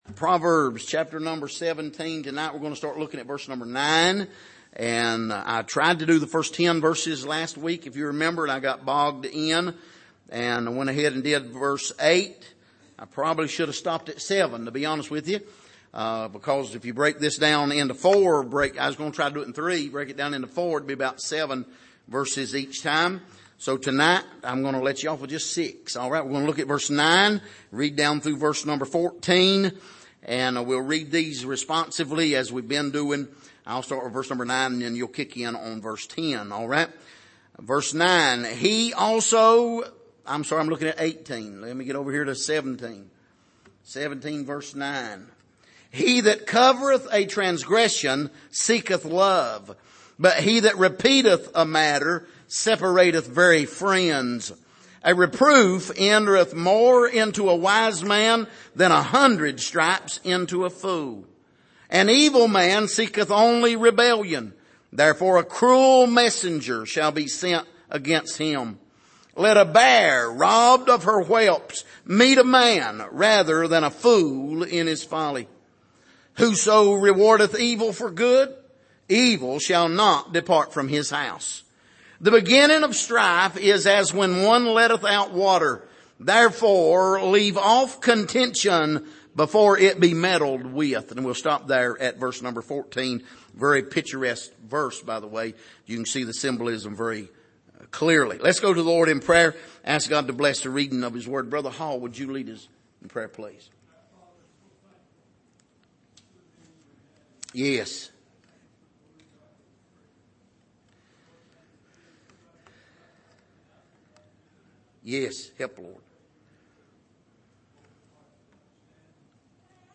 Passage: Proverbs 17:9-14 Service: Sunday Evening